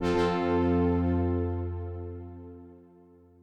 LevelUp.wav